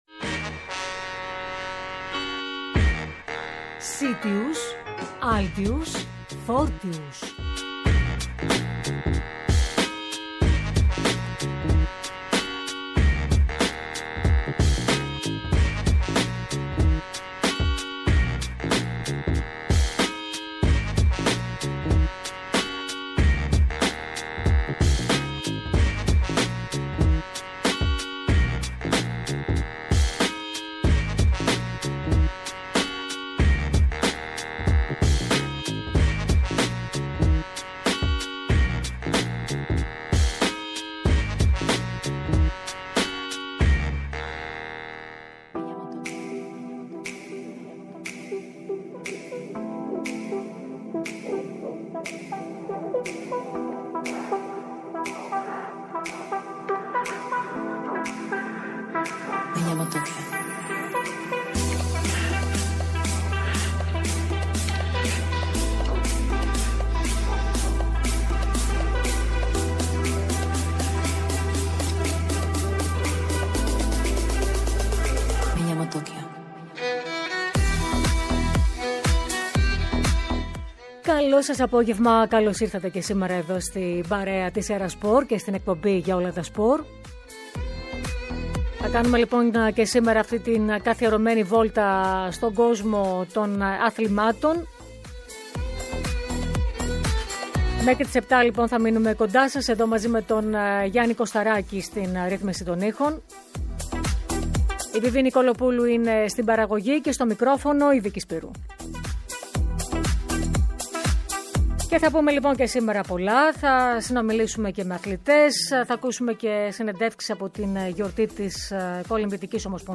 Κοντά μας σήμερα, ήταν: οι δυο αθλητές της καλλιτεχνικής κολύμβησης